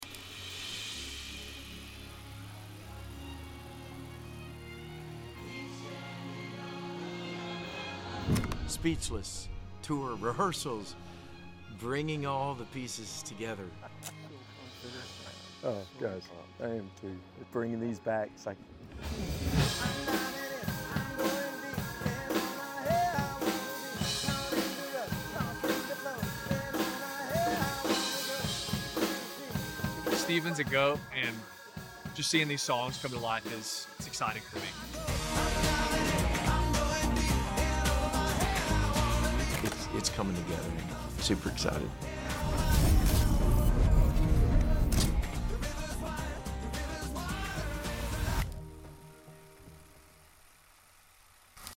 Tour rehearsals is a wrap.